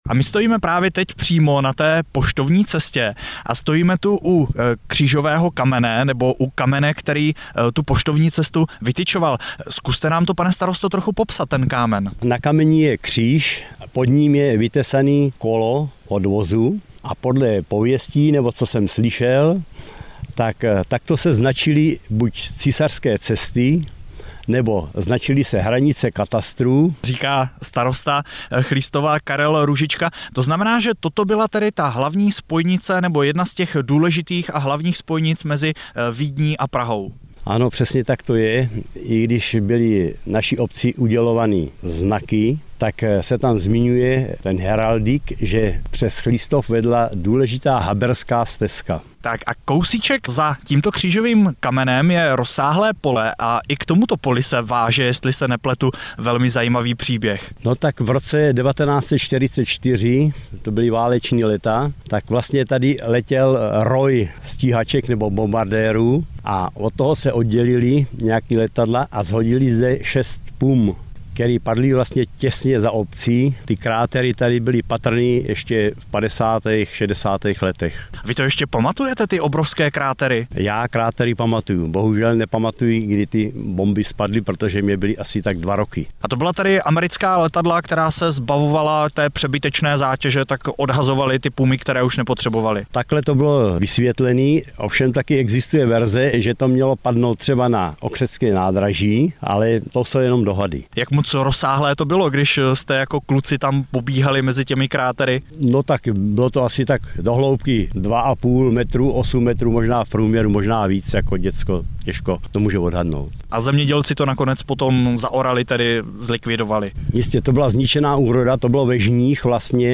09.12.2009 - Rozhlasové vysílání